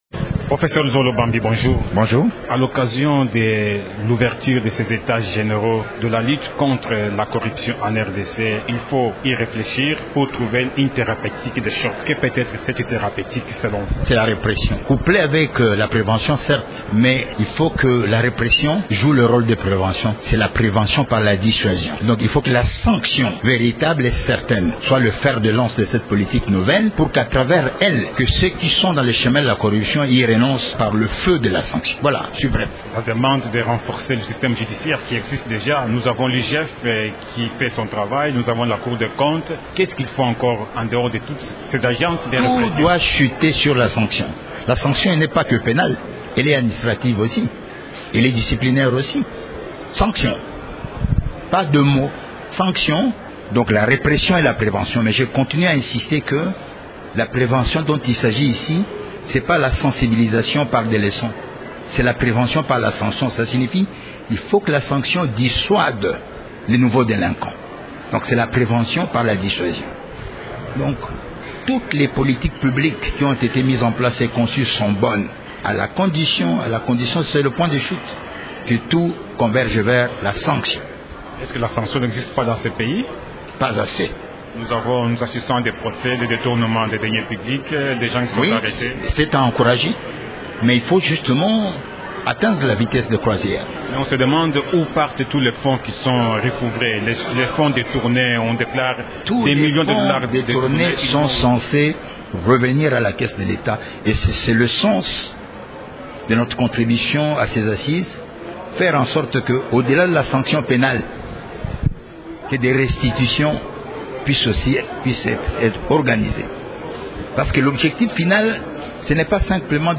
La corruption reste ancrée dans la société congolaise et freine son développement, malgré les institutions et la multitude de textes nationaux et internationaux relatifs à sa répression. Le professeur Luzolo Bambi, ancien ministre de la Justice, ancien conseiller spécial de l’ex-président Joseph Kabila et l’un des participants aux états généraux de la lutte contre la corruption, parle de l’application des textes et la réactualisation du Code pénal congolais.